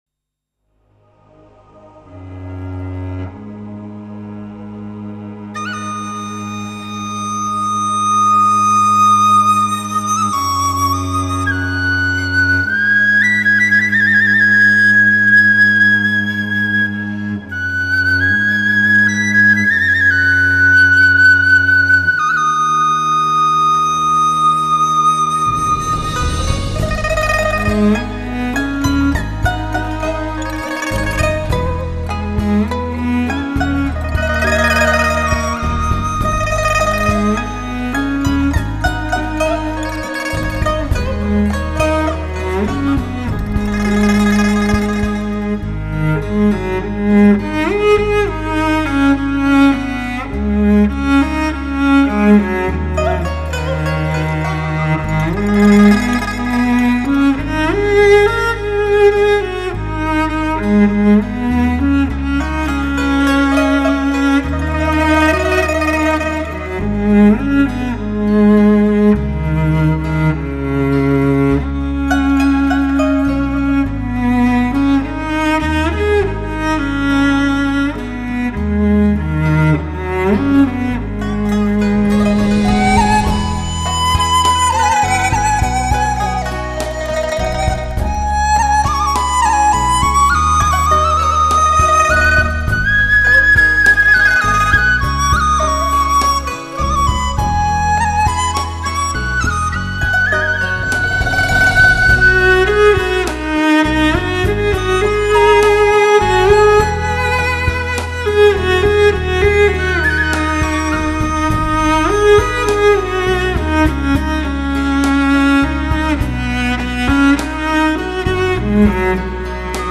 大提琴
大提琴它的音乐时而华丽
时而朦胧 时而低沉 时而富有歌唱性
具有人生般的感染力 音乐抒情 娓娓道来